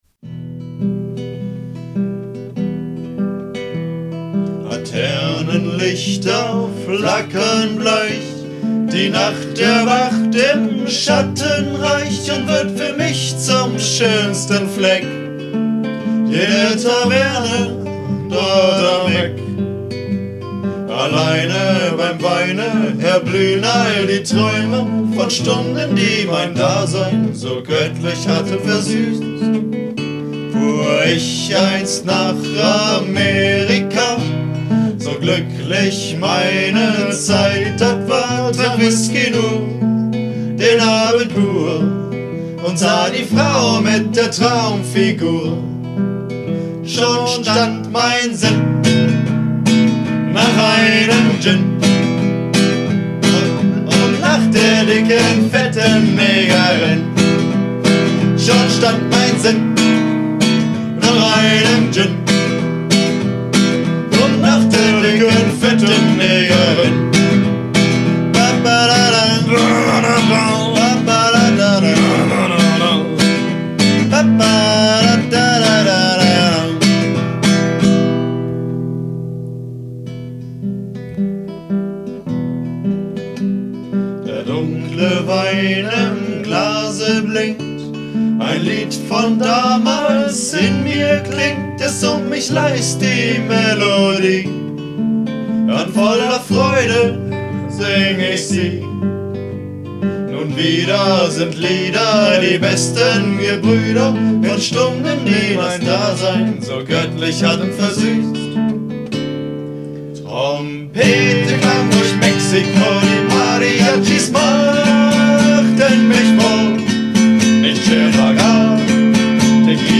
Gesang